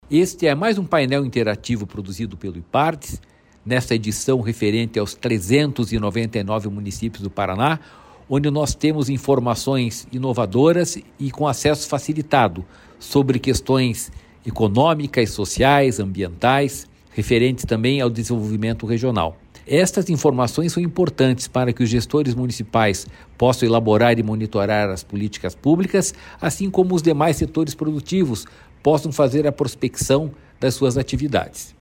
Sonora do presidente do Ipardes, Jorge Callado, sobre os painéis com dados personalizados dos 399 municípios e das regiões